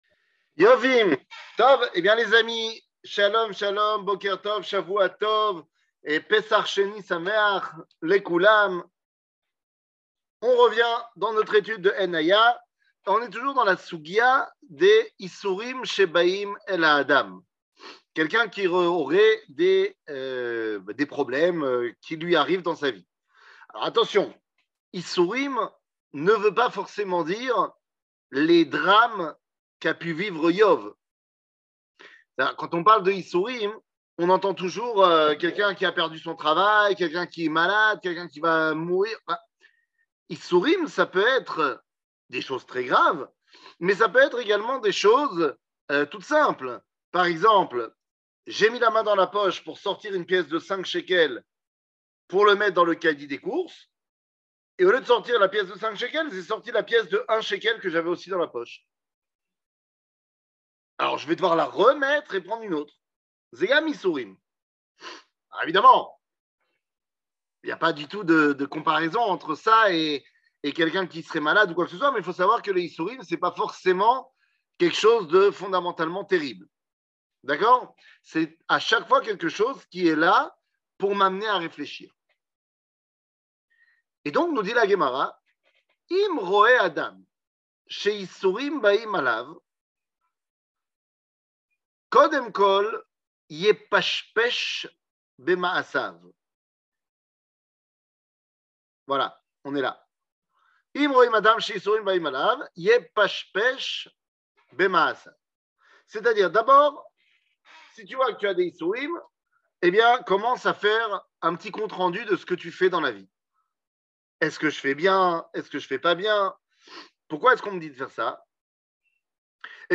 קטגוריה Ein Haya Partie 21 00:45:55 Ein Haya Partie 21 שיעור מ 15 מאי 2022 45MIN הורדה בקובץ אודיו MP3